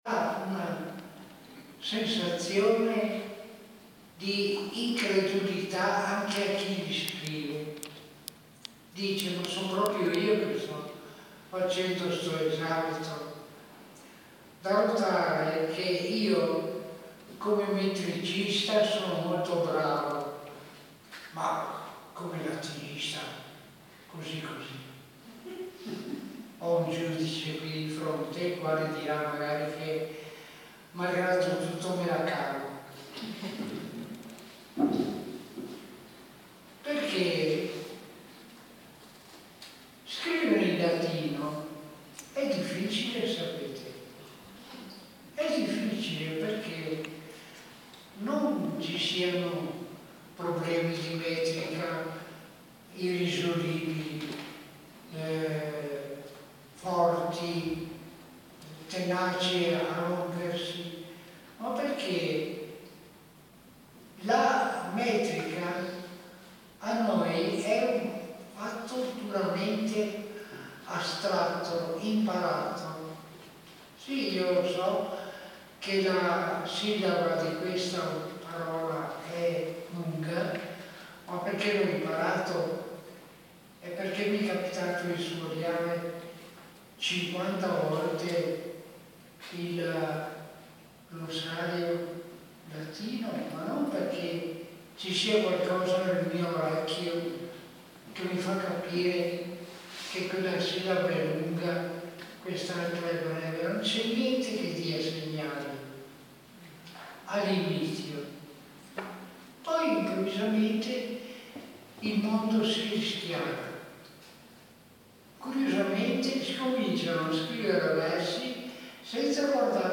Nell'ambito del ciclo dedicato alle lingue classiche del Festival della Letteratura di Mantova, si è svolto ieri nella Sagrestia di San Barnaba un incontro dal titolo Il moderno in lingua antica, conversazione tra il poeta vicentino Fernando Bandini (1931)